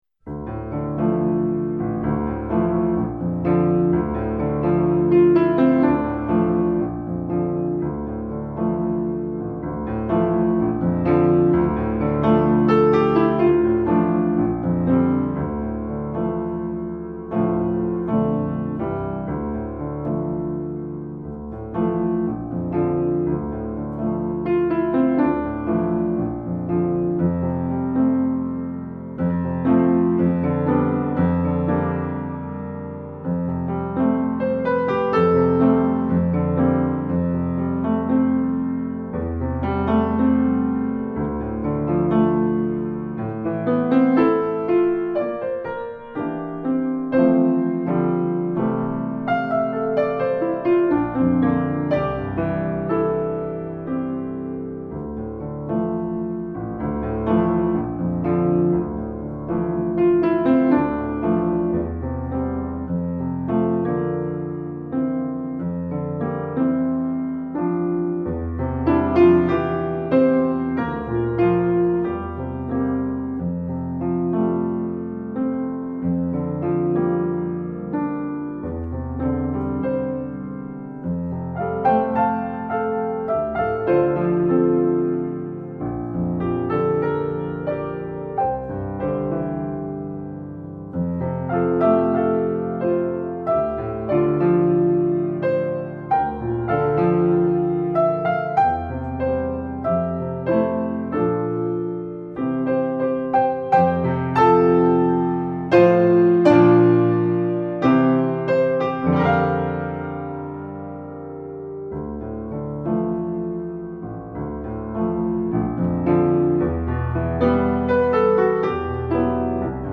Accompaniment – Low Voice
Encore26-Level-2-Pilgrim-Song-Low-ACC-new.mp3